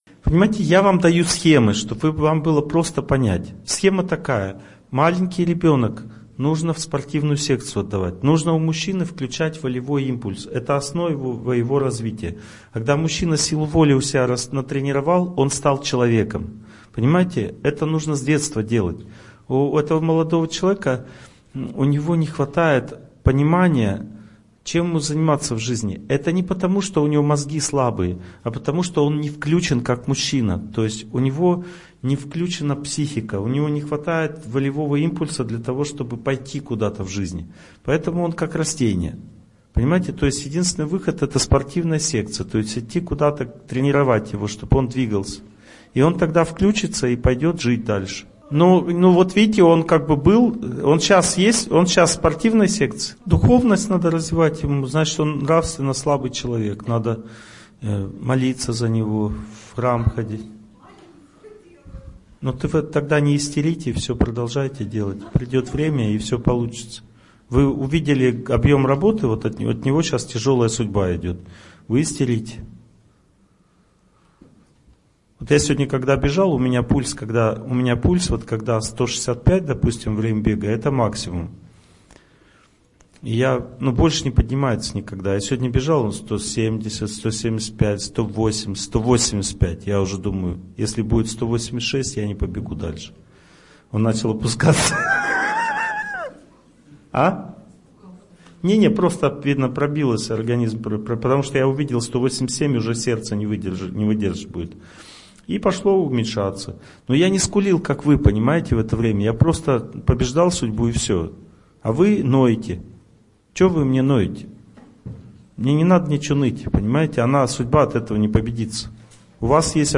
Аудиокнига Преодоление стресса. Глава 2 | Библиотека аудиокниг